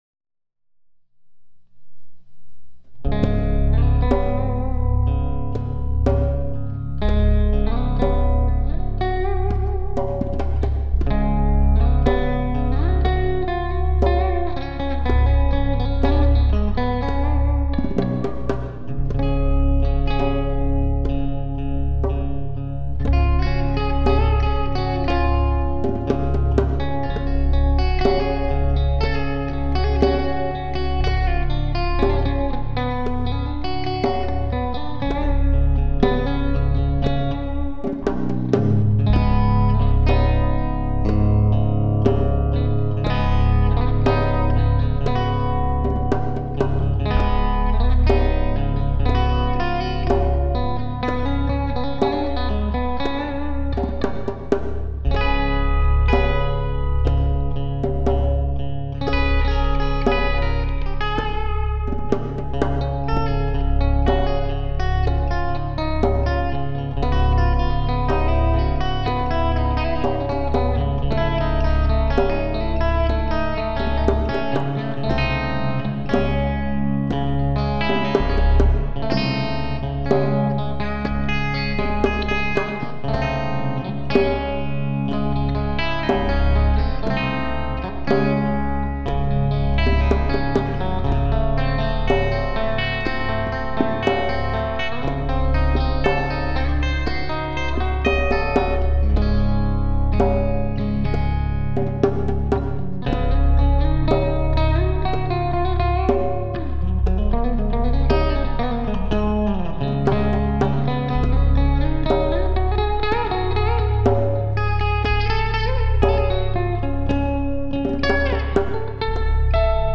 a mixture of Western folk & Hindustani Ragas